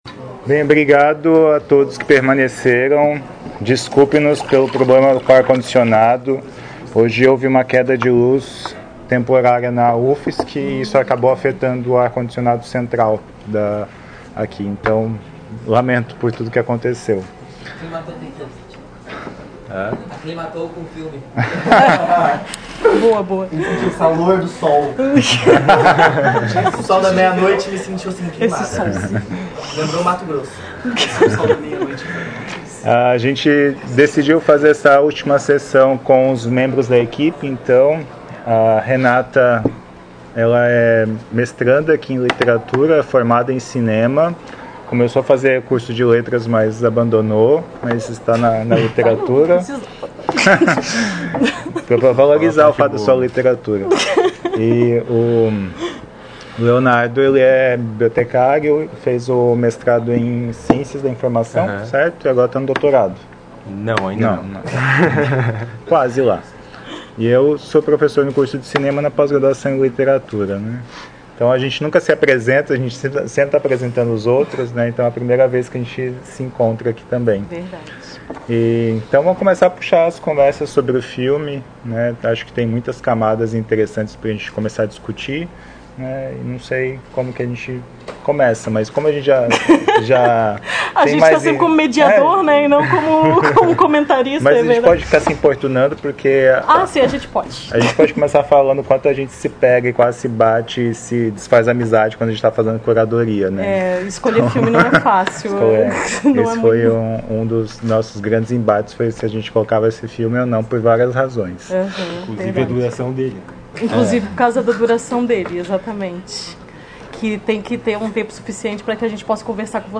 Comentários do(a)s debatedore(a)s convidado(a)s